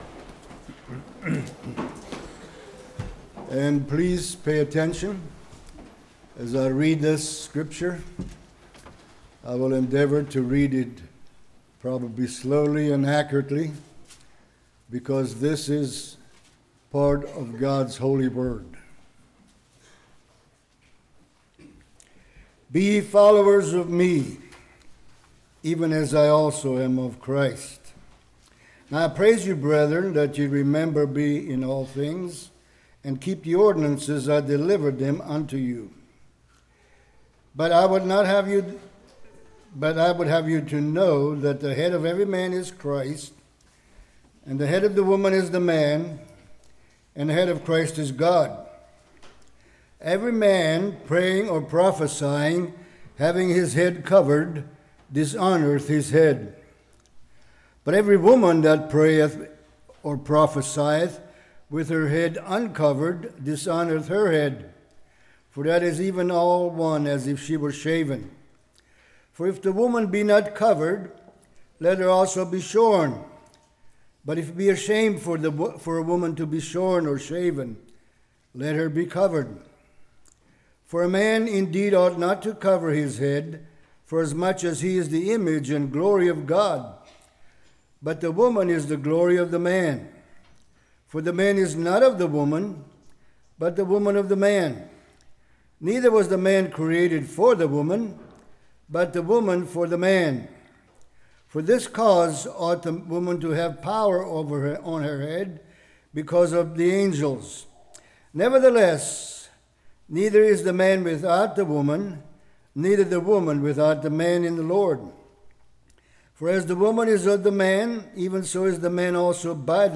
Spring Lovefeast 2017 Passage: 1 Corinthians 11:1-34 Service Type: Morning What part do we have?